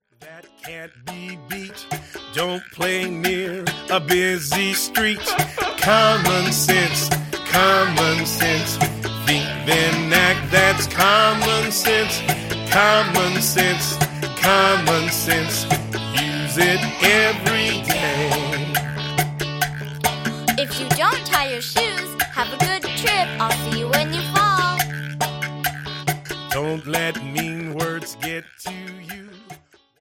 • MP3 of both vocals and instrumental